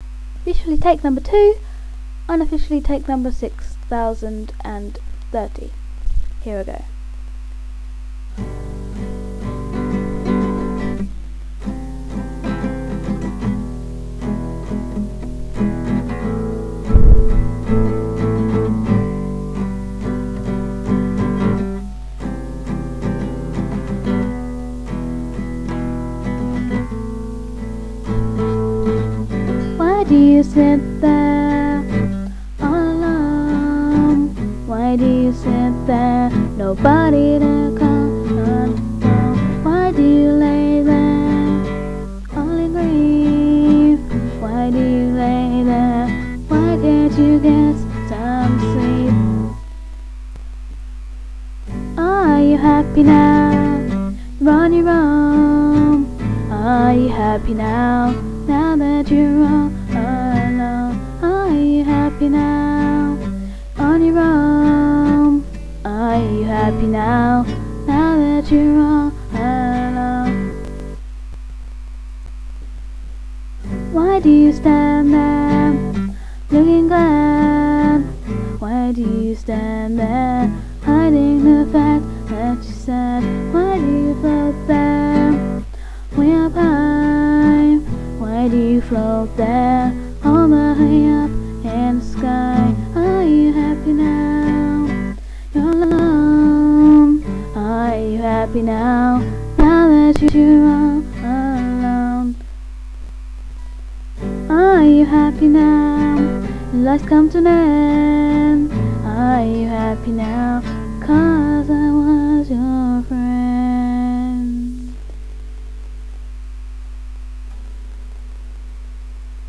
My songs are written by me with the aid of my trusty guitar.
Timings: hit on 1 2 3 & 4
Have a listen, sung by me & played by me, it is in 4 parts. 2.09MB wav file.
This is the second, vocal version 2.51MB wav file.